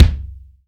LA KICK 1.wav